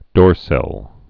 (dôrsĭl)